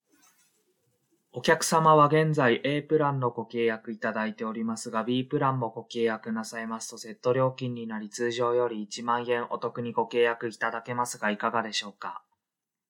抑揚のない話し方だとどうなる？
抑揚のない話は、棒読みやカタコトのように聞こえます。
抑揚のない話し方.mp3